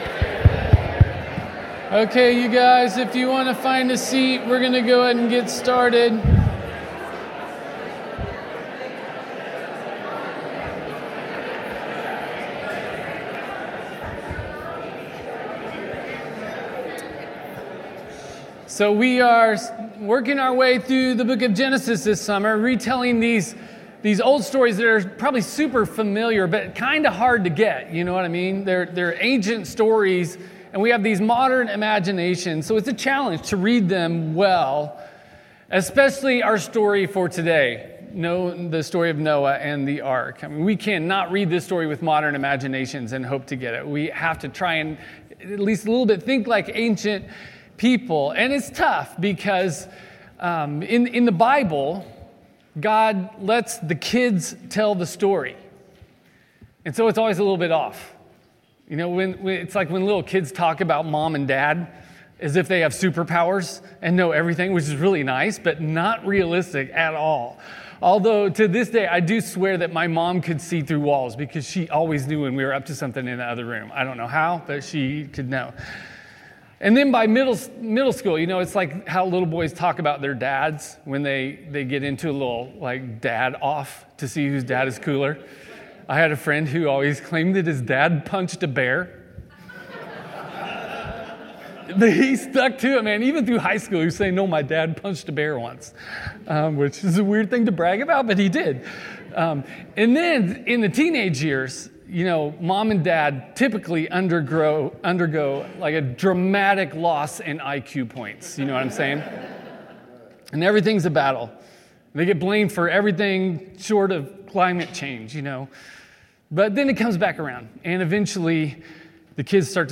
SERMON.7.13.25.mp3